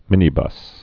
(mĭnē-bŭs)